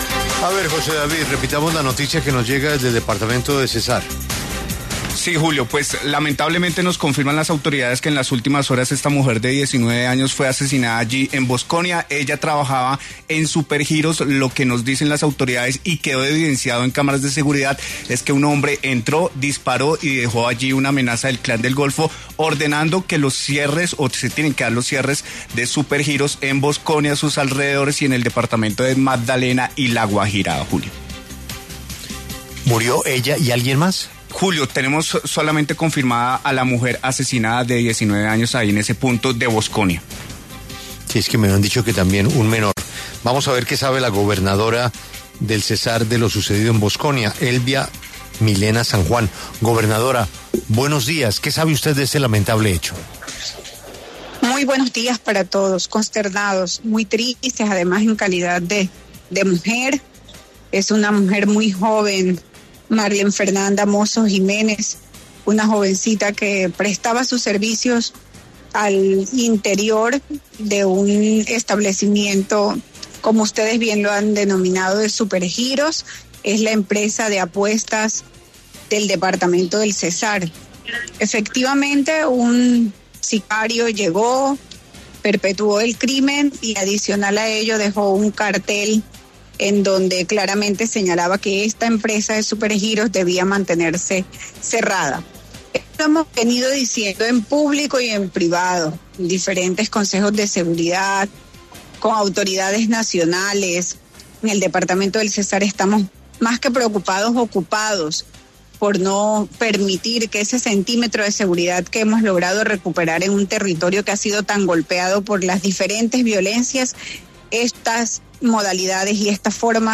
En diálogo con La W, la gobernadora del departamento señaló que han aumentado las extorsiones en varios municipios, por lo que hizo un llamado al Gobierno Nacional.